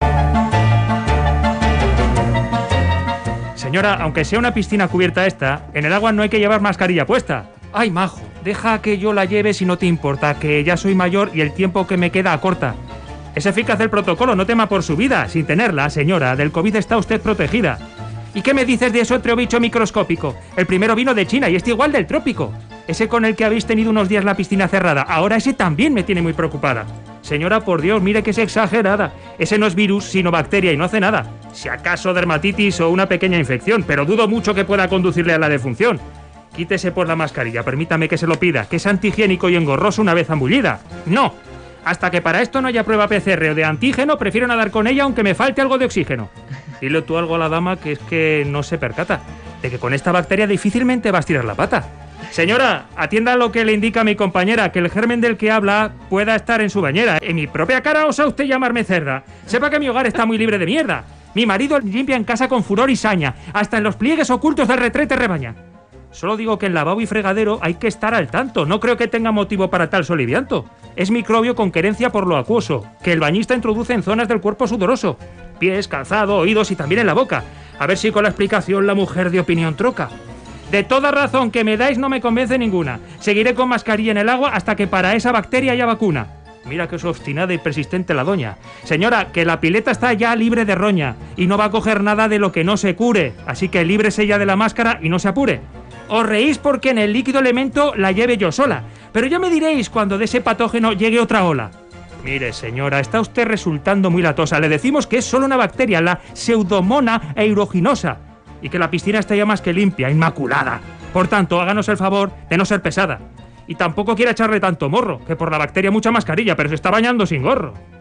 Crónica en verso: Bacterias en las piscinas de Vitoria